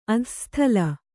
♪ adhassthala